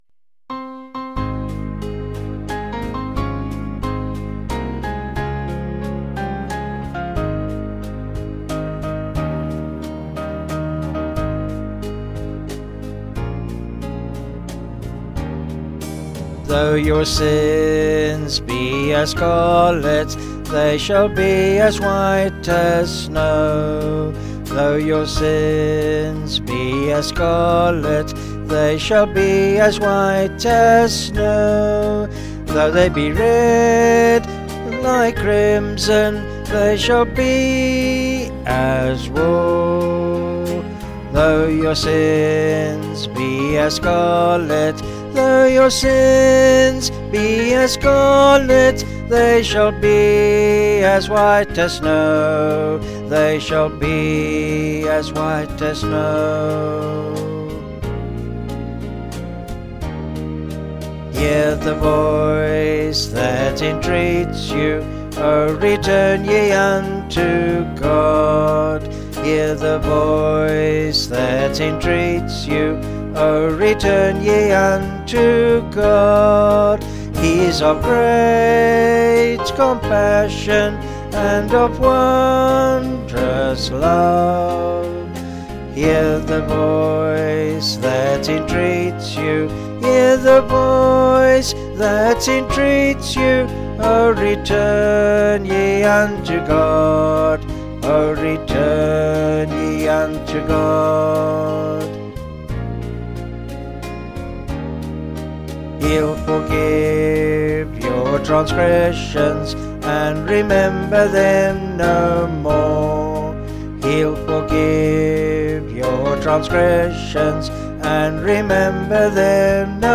Vocals and Band   263.3kb Sung Lyrics